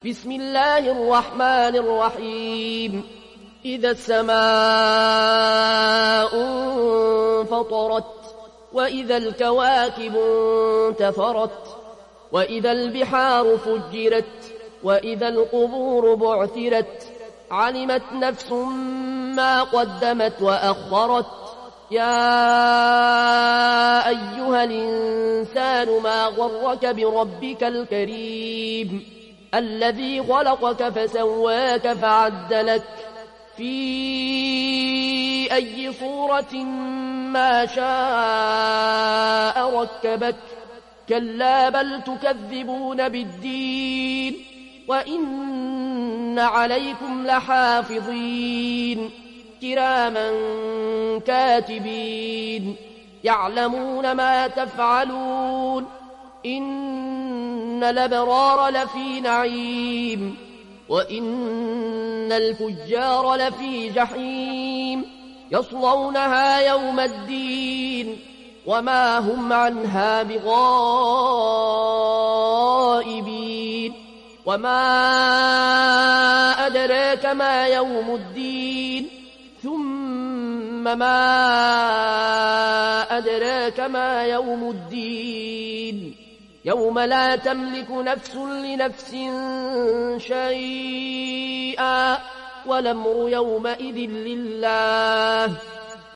برواية ورش عن نافع